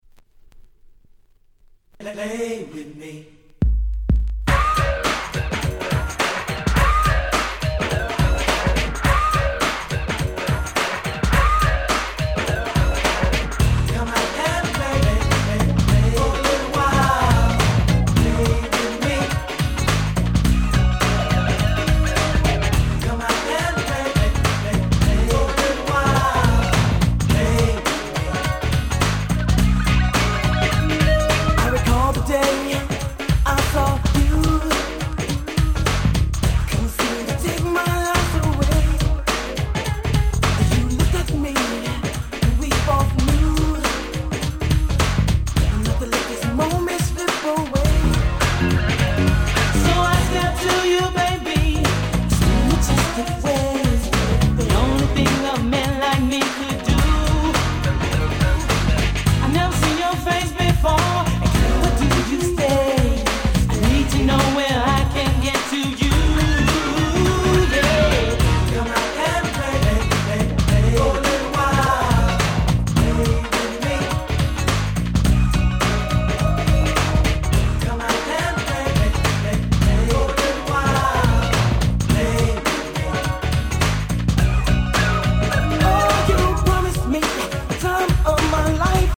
92' 人気New Jack Swing！！